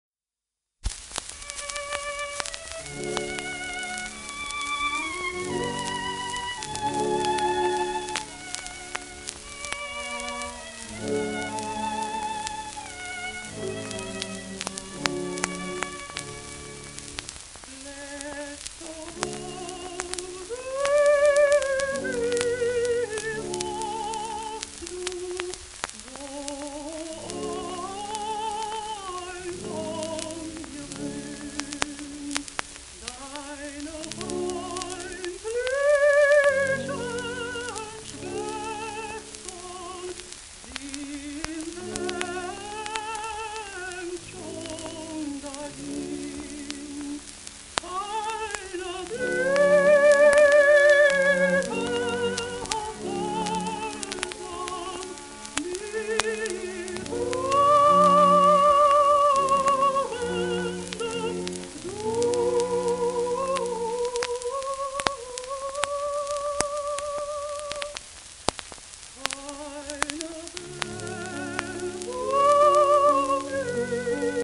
w/ピアノ、ヴァイオリン、チェロ
盤質A- *ニキビキズ数カ所、音あり
1928年頃録音
ドイツ、フラアンクフルト出身のソプラノ。